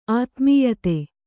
♪ ātmīyate